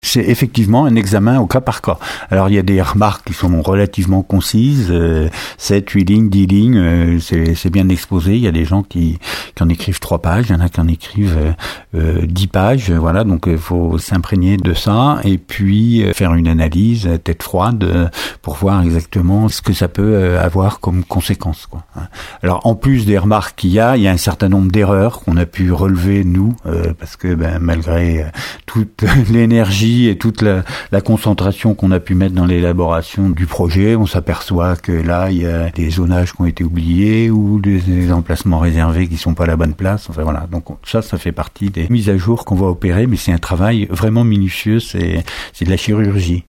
Jean Gorioux, le président de la CdC Aunis Sud :